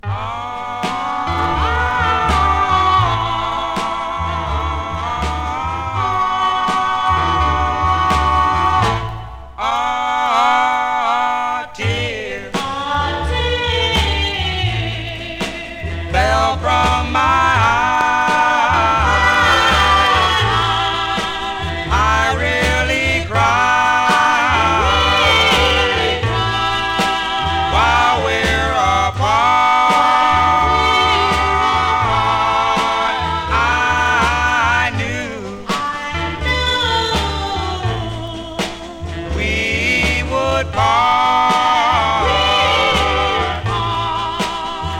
勢いを感じるボーカルワークと。
Doo Wop, Rhythm & Blues　UK　12inchレコード　33rpm　Mono